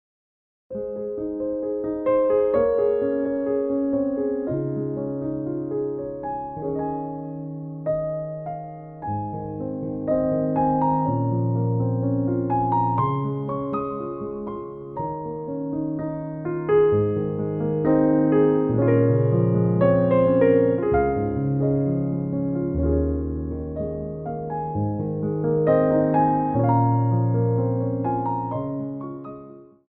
Piano Arrangements of Popular Music
4/4 (16x8)